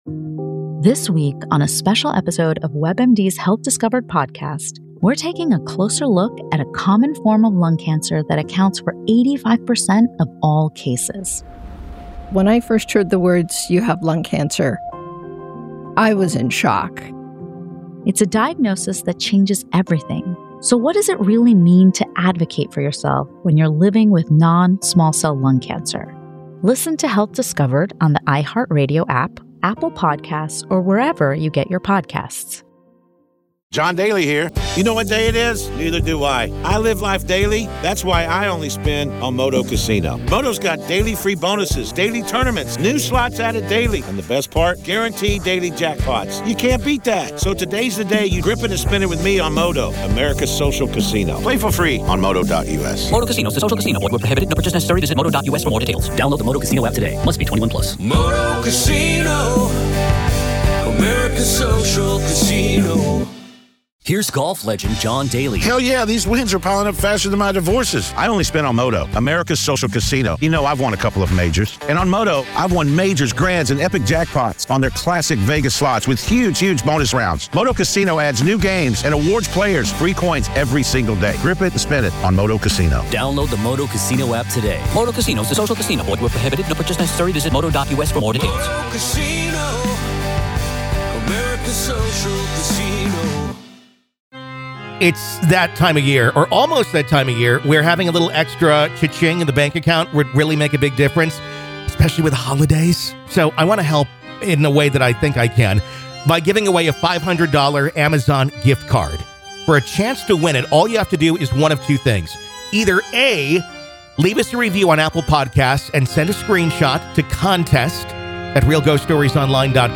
Real Ghost Stories Online | OPEN LINE 📞